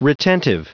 Prononciation du mot retentive en anglais (fichier audio)
Prononciation du mot : retentive